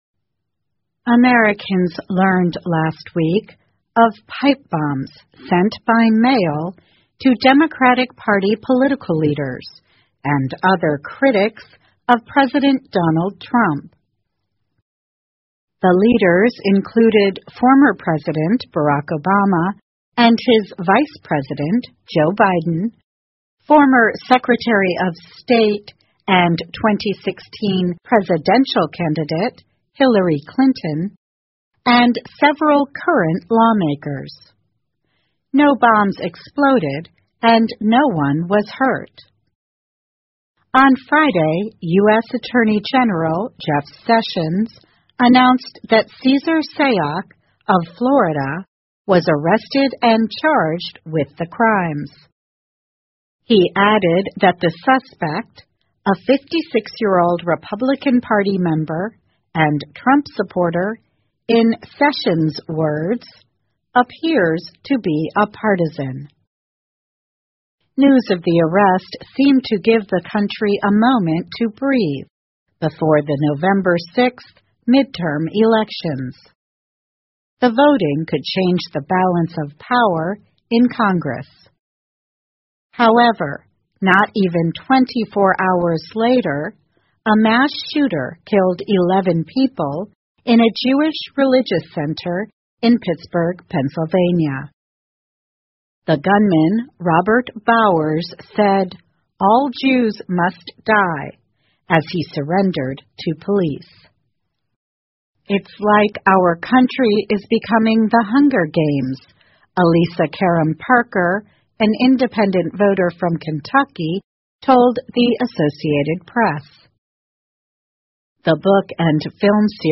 VOA慢速英语--Americans to Vote Amid Violence, Anger 听力文件下载—在线英语听力室